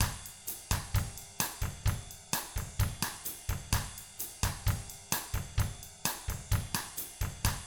129BOSSAT3-L.wav